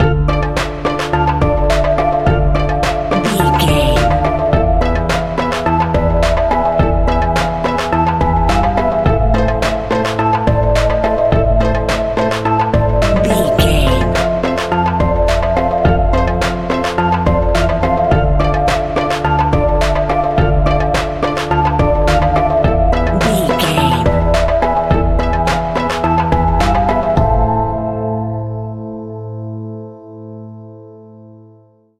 Aeolian/Minor
tension
ominous
eerie
synthesiser
electric piano
strings
drums
spooky
horror music